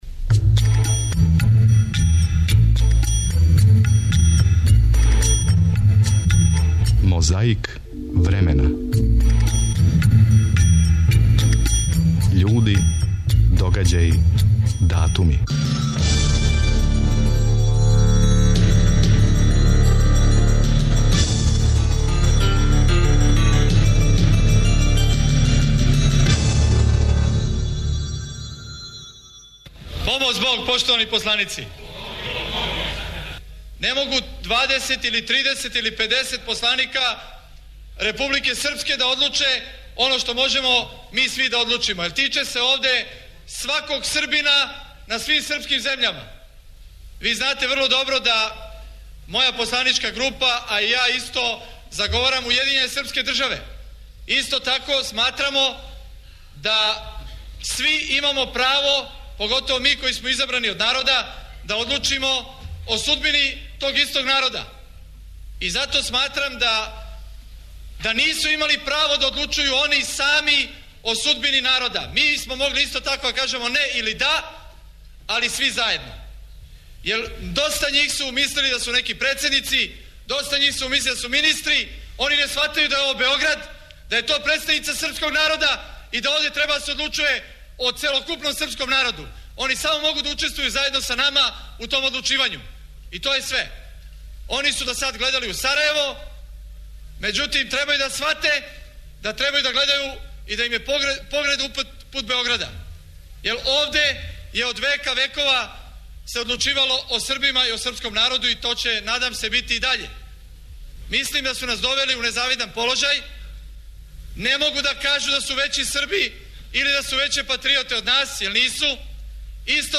И коначно, на данашњи дан 1992. п роглашен је Устав Савезне Републике Југославије. 28. априла 1993 . заседање Народне Скупштине Србије.